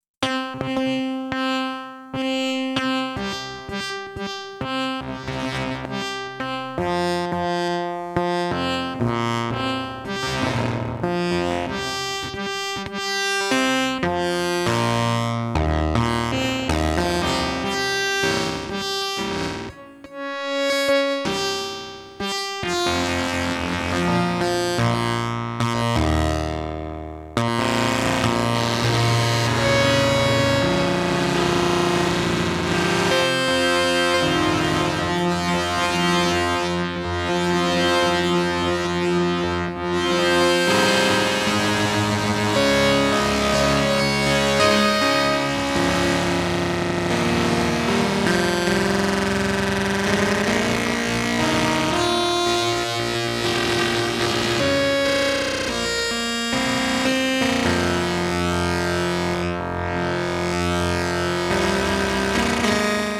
some unstable fm. tried fm’ing everything i could think of in the mod matrix, including other mod slots. very touchy dependent on velocity/pressure: